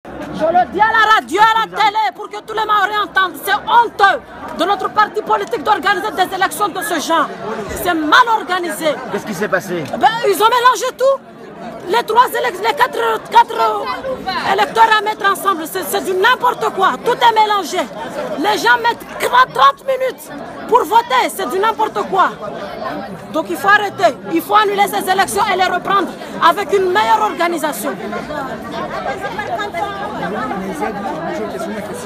Elections Les Républicains : une désorganisation totale ! (interview d’une électrice)
Interview-dune-électrice.m4a